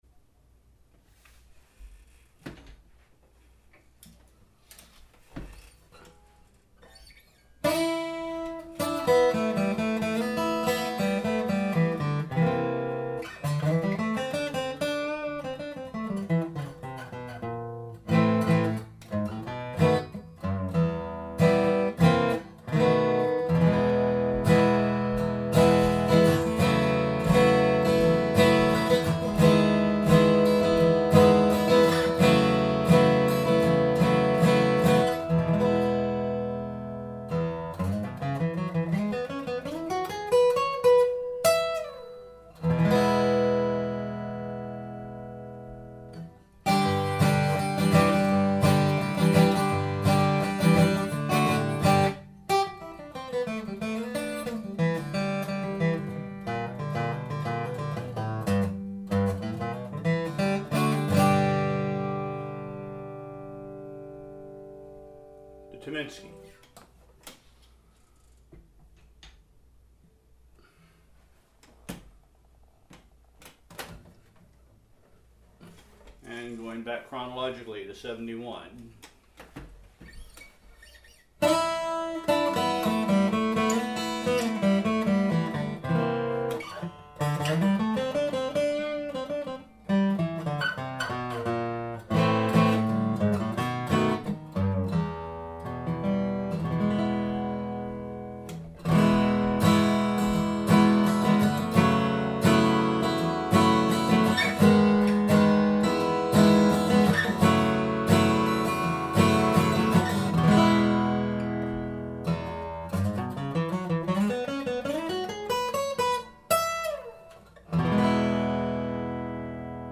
Здесь; послушайте три из них: мой новый '09 D-28 Tyminski ', который с смещенными сзади зубчатыми связями является самым тяжелым басом из всех; мой 71 год, который у меня был всю жизнь; и мой 67 год, который я получил от его семьи после смерти моего лучшего друга.